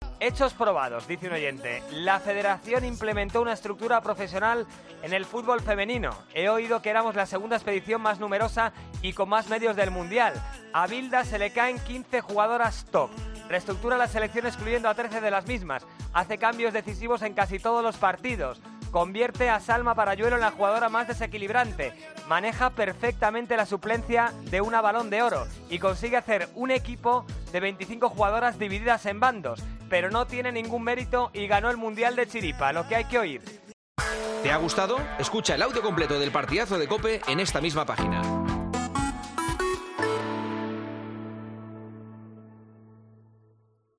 Un oyente de El Partidazo de COPE explica por qué la RFEF no debe despedir a Vilda: "Hecho probado"